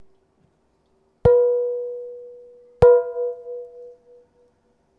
マイクロフォンは、SONYのワンポイントステレオ録音用、ECM-MS907を使いました。
3 同じフライパンですが、二打目に、打った瞬間マイクロフォンの前でフライパンを左右に回転させてみたもの。
音は” わん、わん、わん、、、”ってな感じです。
fling-pan2.wav